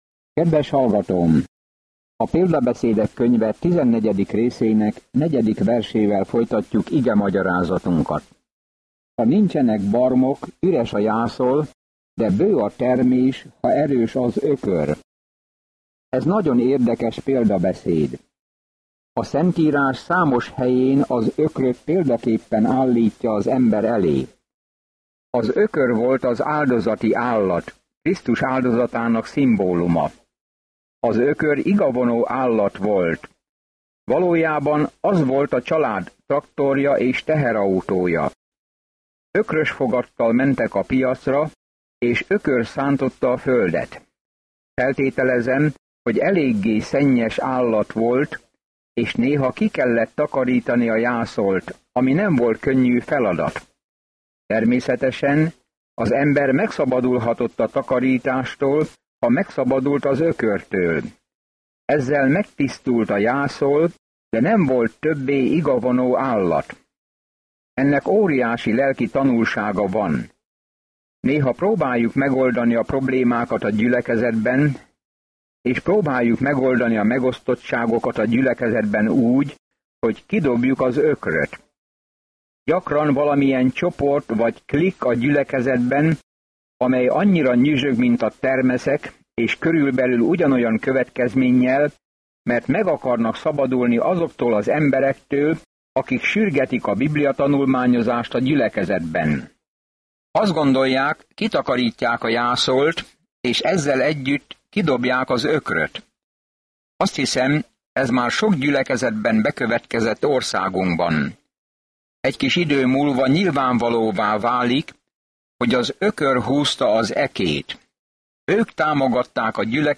Napi utazás az Példabeszédek, miközben hallgatja a hangos tanulmányt, és olvassa el Isten szavának kiválasztott verseit.